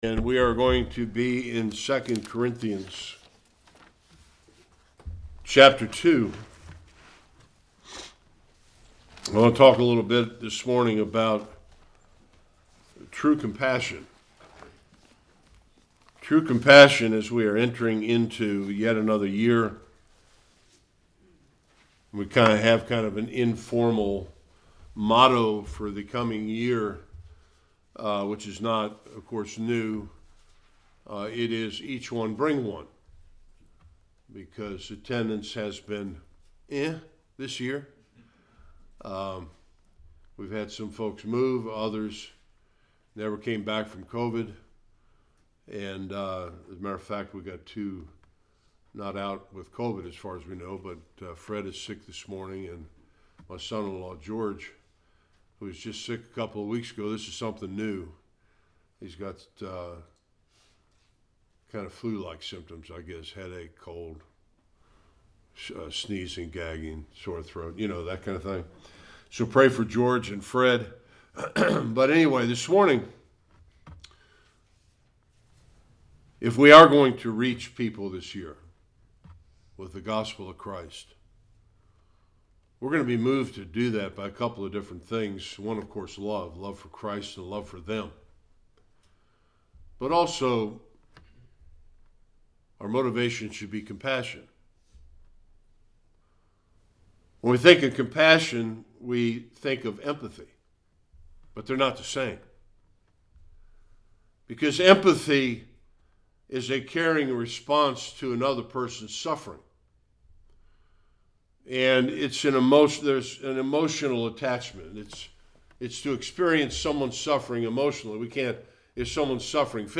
1-17 Service Type: Sunday Worship Paul dealt with much heartache in Corinth